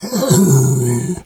bear_roar_soft_03.wav